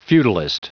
Prononciation du mot feudalist en anglais (fichier audio)
Prononciation du mot : feudalist